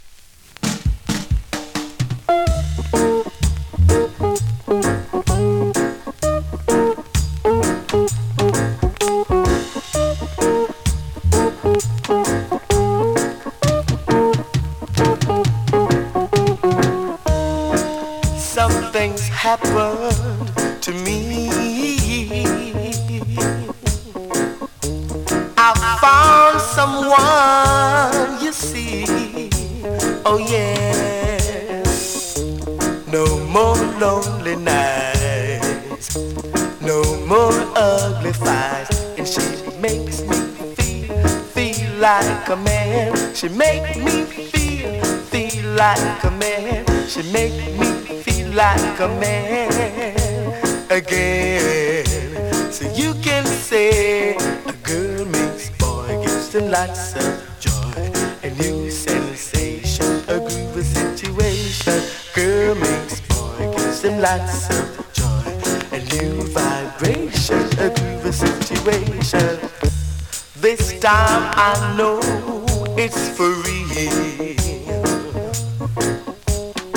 SKA〜REGGAE
スリキズ、ノイズ比較的少なめで